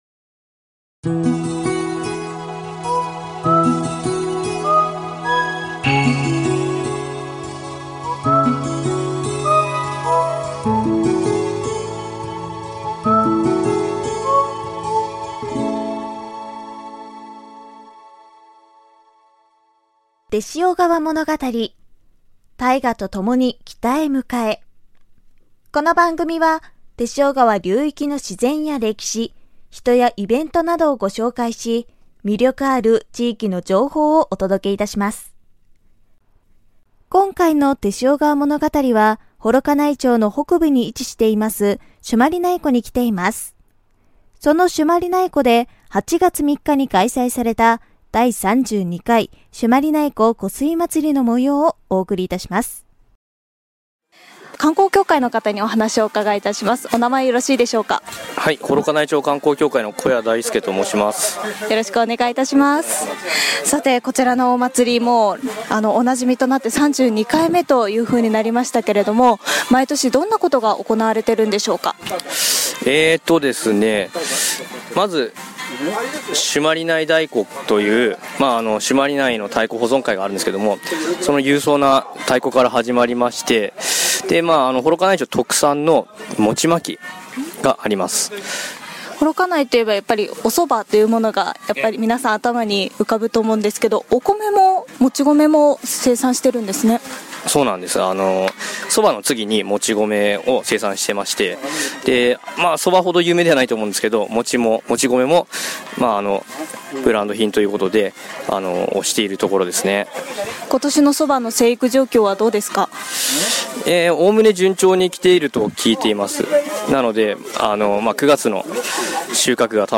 道北の幌加内町朱鞠内湖湖畔で開催された真夏のイベント「朱鞠内湖湖水祭」の会場でお客様やスタッフの皆様にお話をお聞きしました。